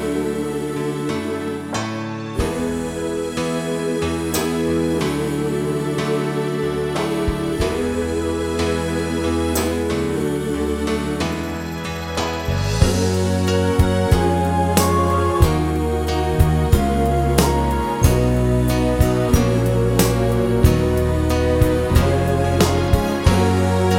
Ooh Backing Vocals Only Soundtracks